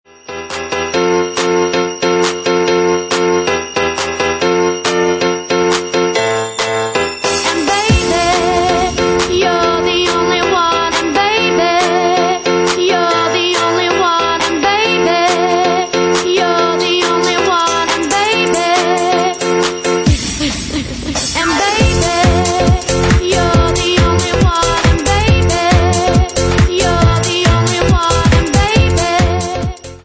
Bassline House at 138 bpm